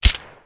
wpn_pistol10mm_silenced.wav